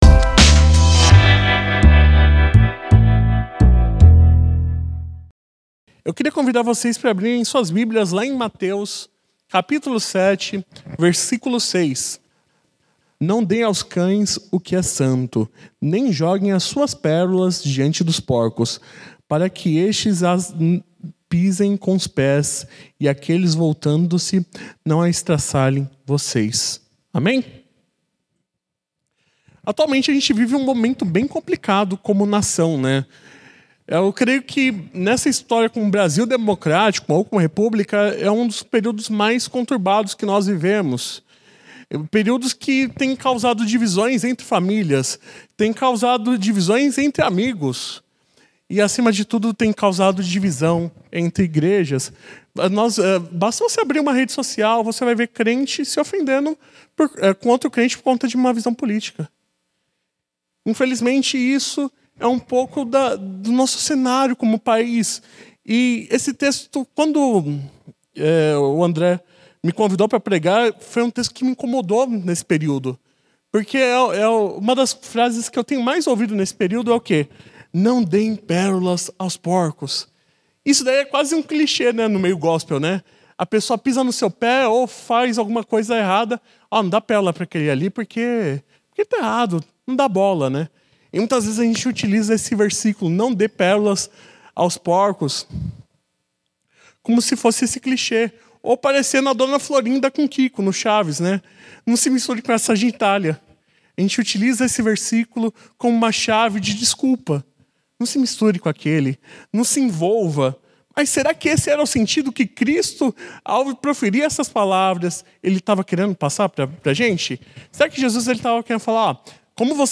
Mensagem realizada no Culto de Reflexão de Oração.